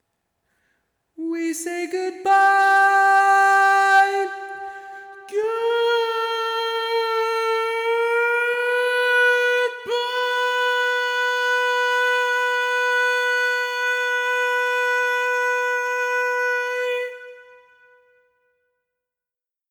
Type: Barbershop
Each recording below is single part only.
Learning tracks sung by